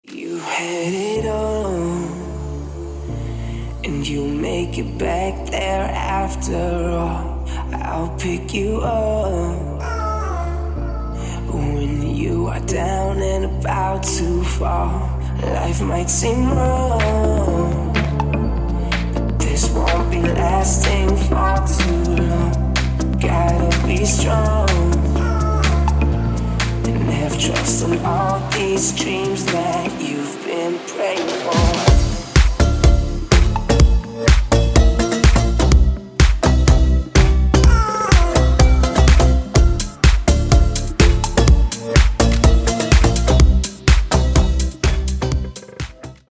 • Качество: 160, Stereo
мужской вокал
deep house
dance
vocal
Завораживающий мужской голос...